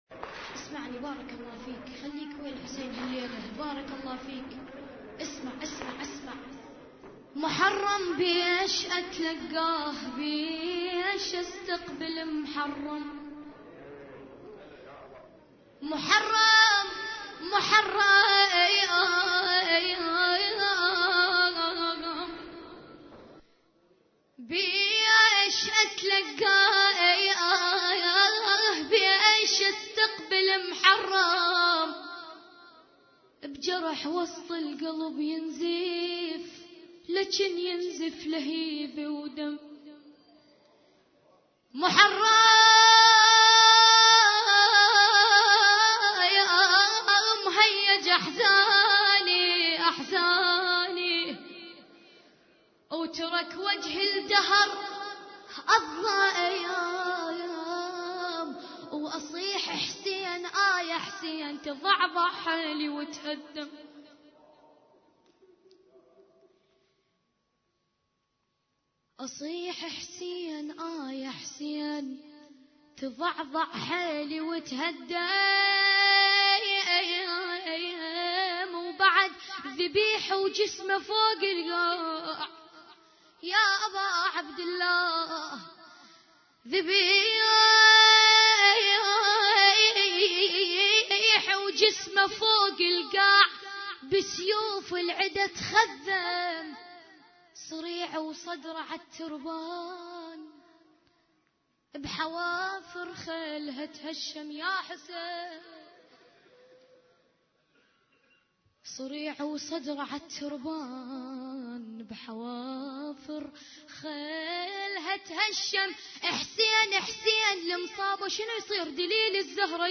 عزاء ليلة 1 محرم 1433 هجري